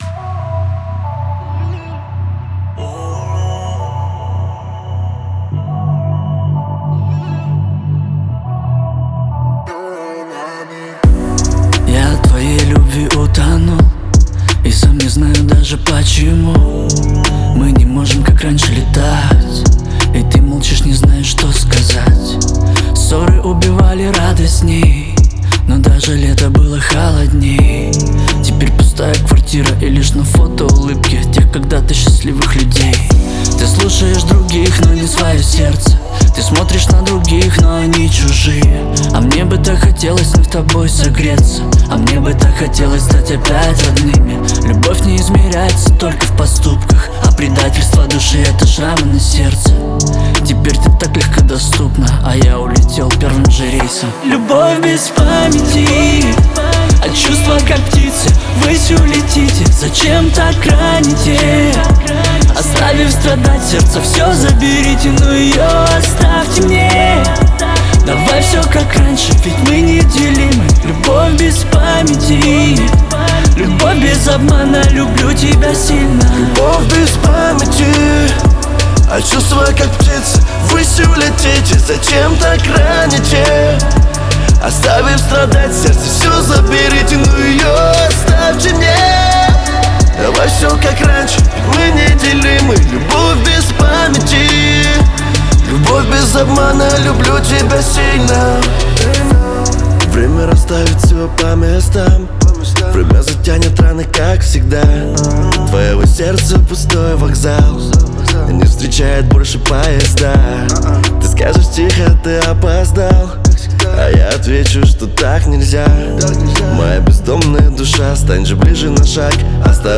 это захватывающая песня в жанре поп